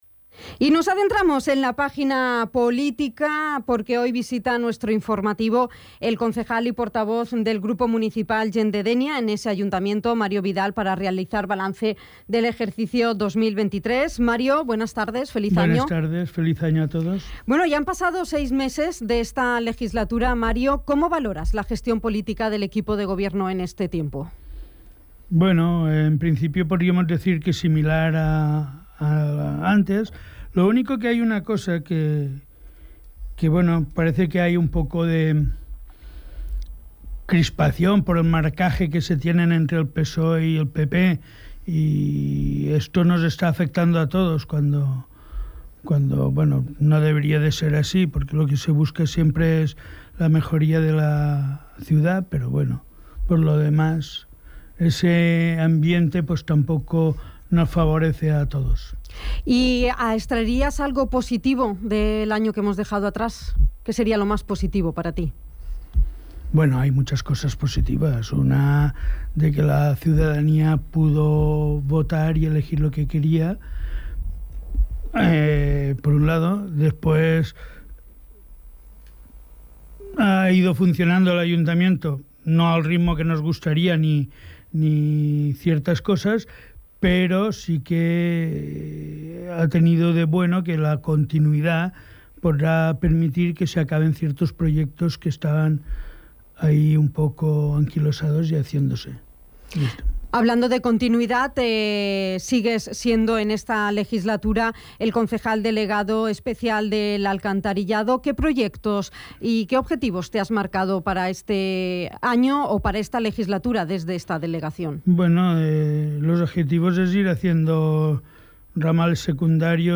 El concejal y portavoz del grupo municipal Gent de Dénia en el Ayuntamiento, Mario Vidal ha visitado la redacción informativa de Dénia FM, para realizar un balance de la gestión municipal de 2023.
Entrevista-Mario-Vidal-1.mp3